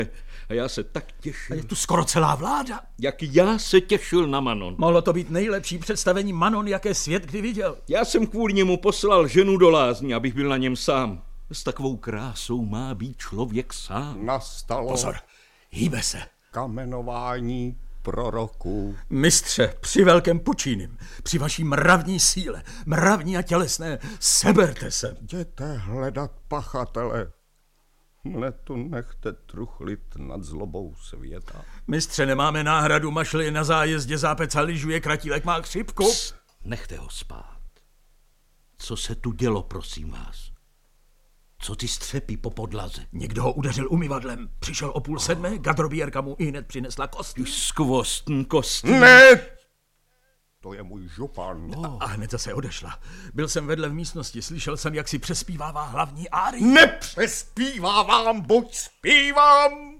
Audiobook
Read: Vlastimil Brodský